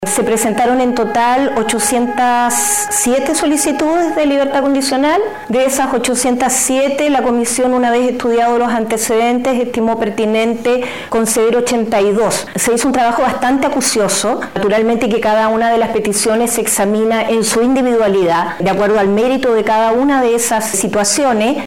La ministra Nancy Bluck, titular de la Corte de Apelaciones de Valparaíso, explicó a Radio Bío Bío que la evaluación se basó en criterios legales y técnicos, incluyendo informes de Gendarmería, antecedentes de conducta y progresión en el cumplimiento de condena.